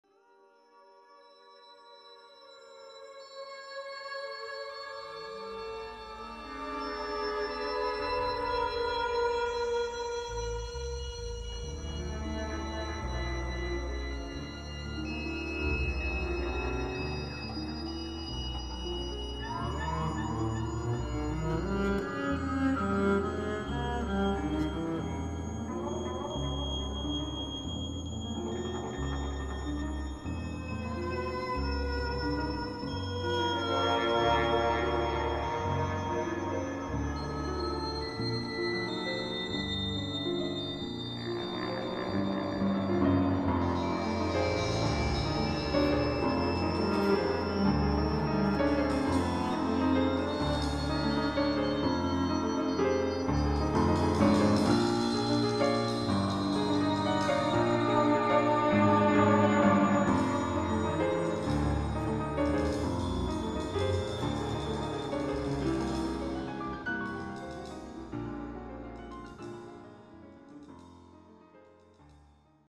Please note: These samples are not of CD quality.
for Bass, Piano and Tape